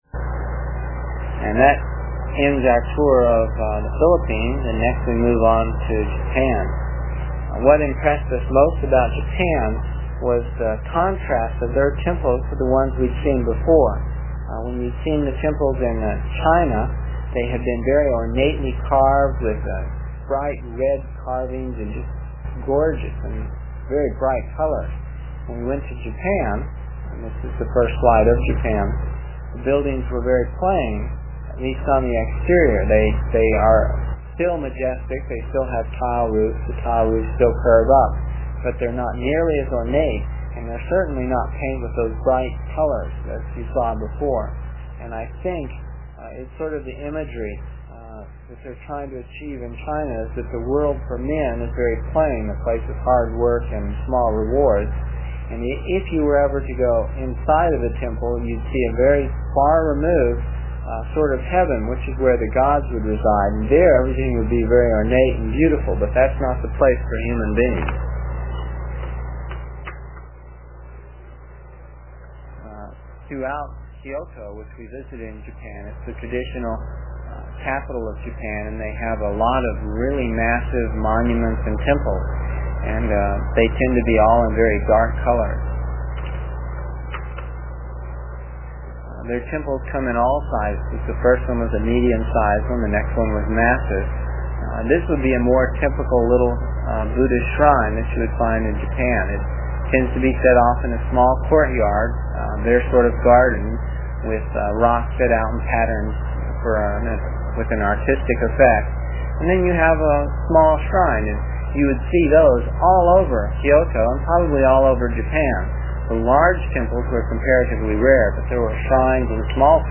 It is from the cassette tapes we made almost thirty years ago. I was pretty long winded (no rehearsals or editting and tapes were cheap) and the section for this page is about eight minutes and will take about three minutes to download with a dial up connection.